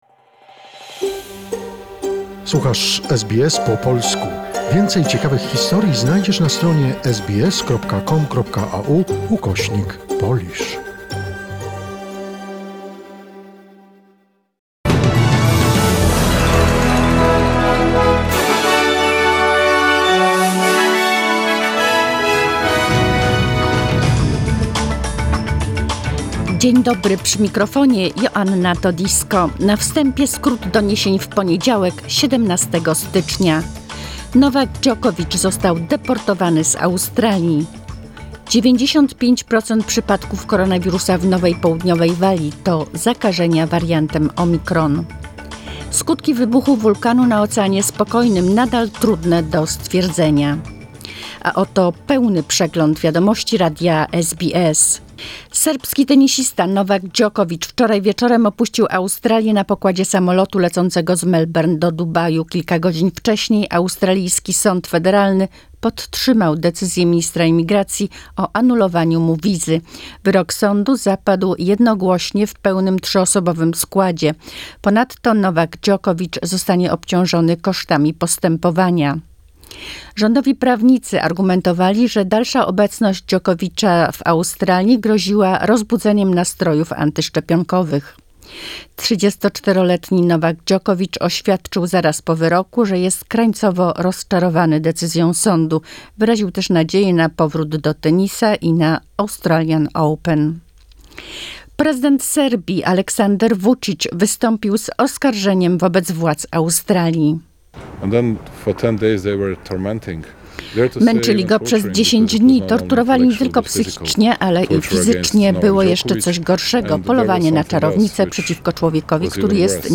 SBS News in Polish, 17 January 2022